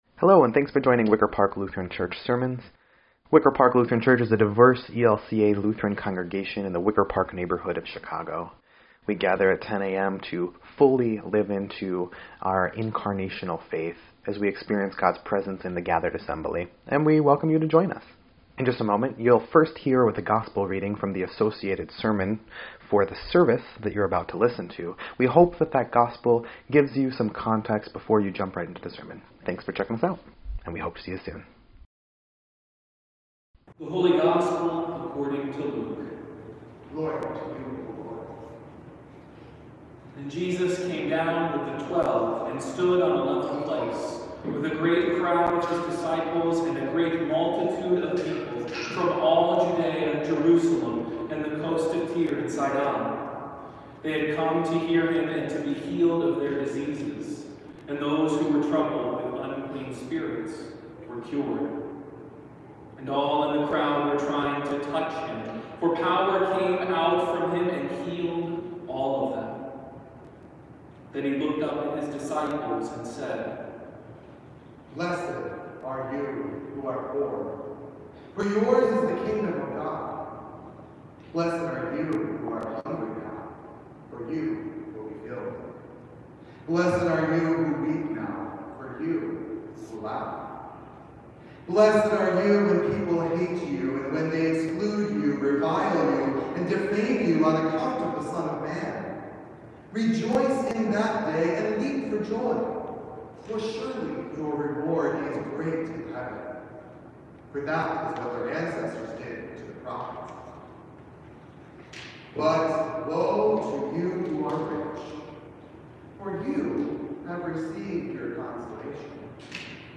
2.13.22-Sermoln_EDIT.mp3